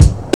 Kick (75).wav